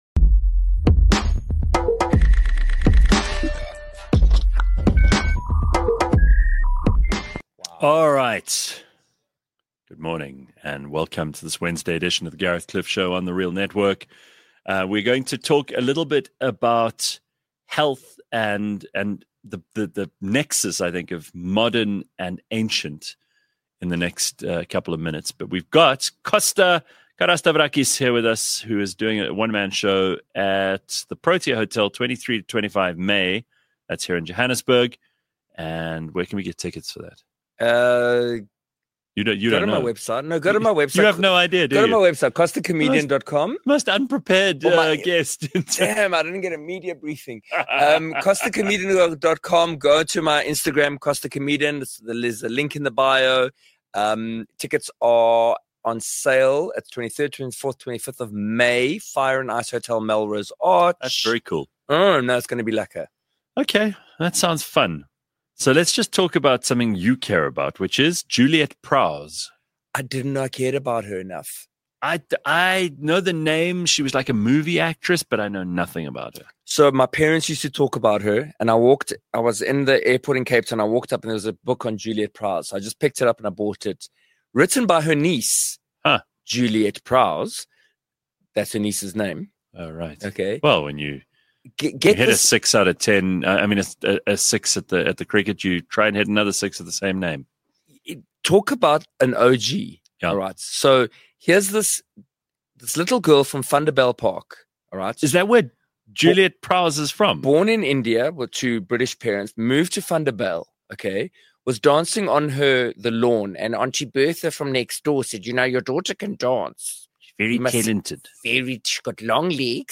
A live podcast show, that’s like a morning radio show, just much better.
Clever, funny, outrageous and sometimes very silly.